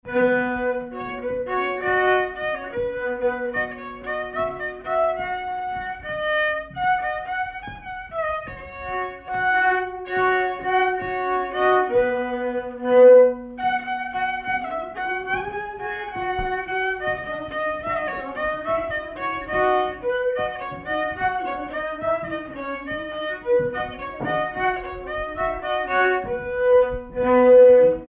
danse : valse
circonstance : bal, dancerie
Pièce musicale inédite